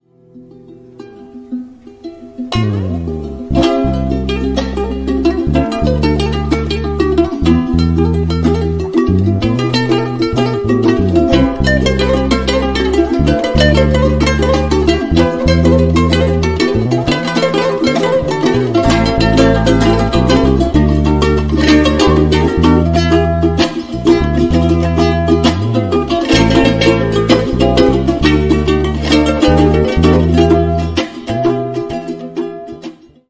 Georgian Musicians Bass Guitar ringtone